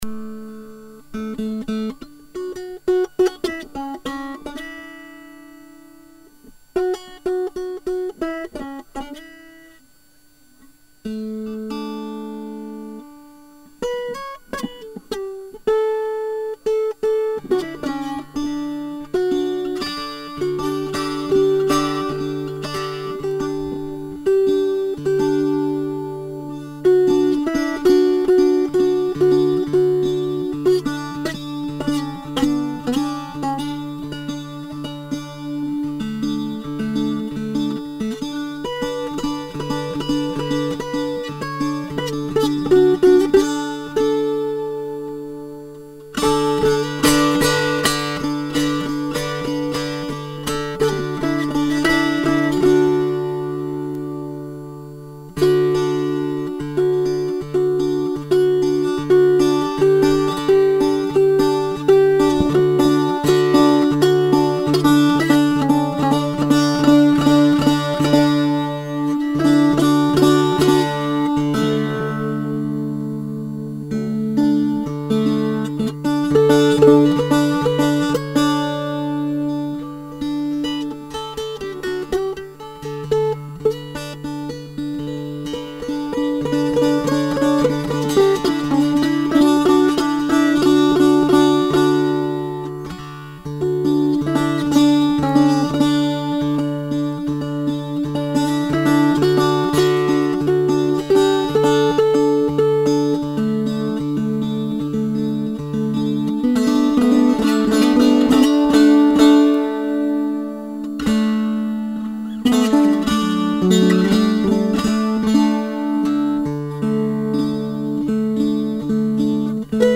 a mood bit